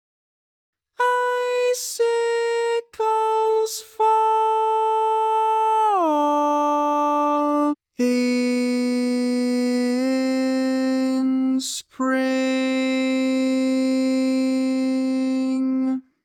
Type: Barbershop
Each recording below is single part only.